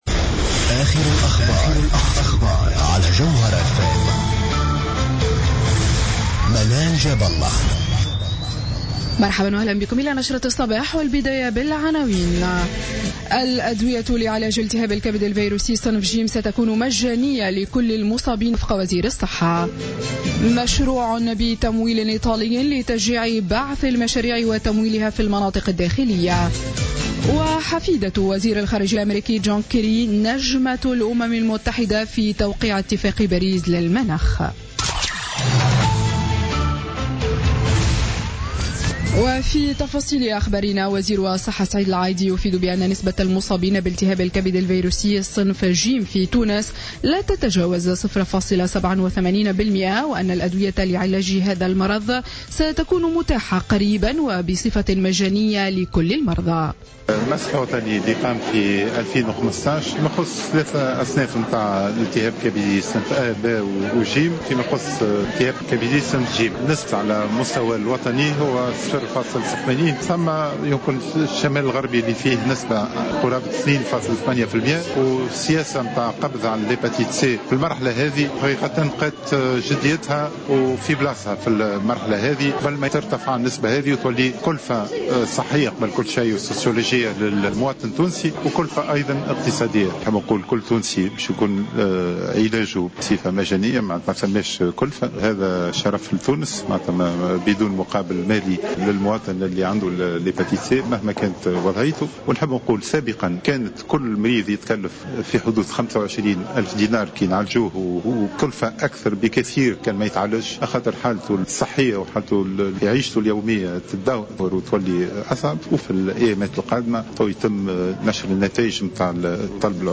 نشرة أخبار السابعة صباحا ليوم السبت 23 أفريل 2016